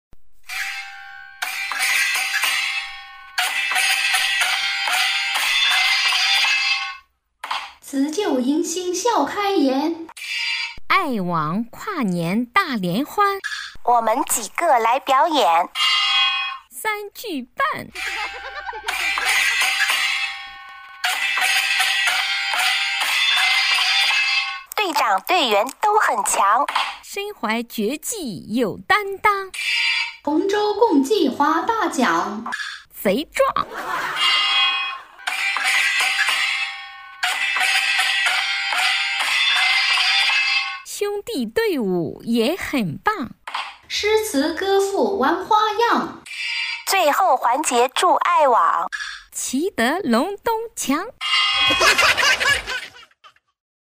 三句半